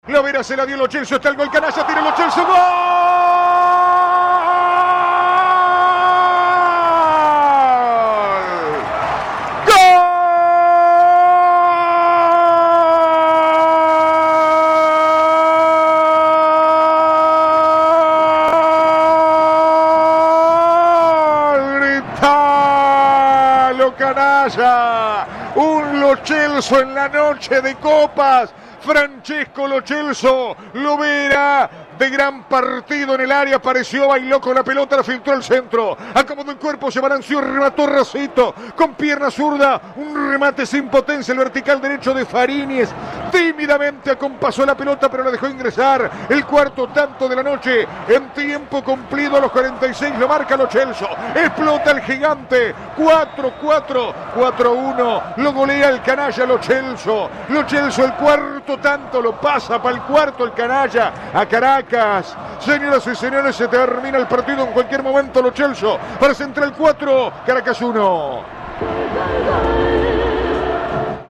relato